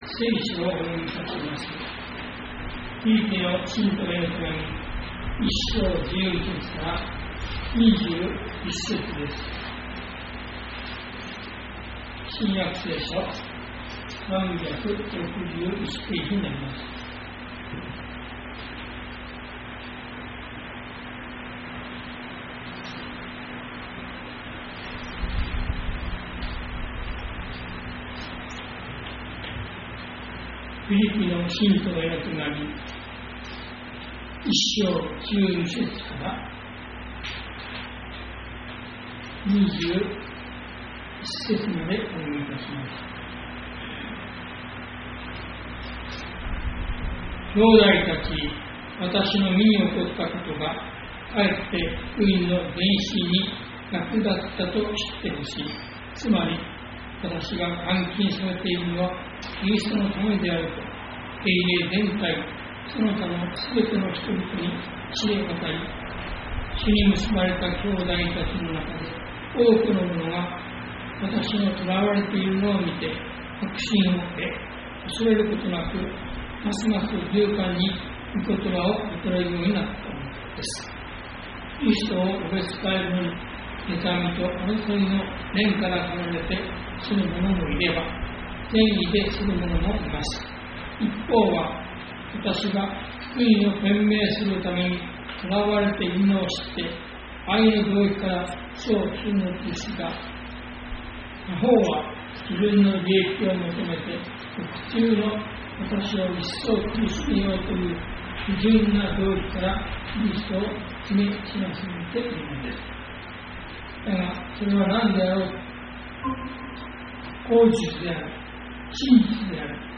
礼拝説教アーカイブ 日曜 朝の礼拝 生きることはキリストであり、死ぬことは益です。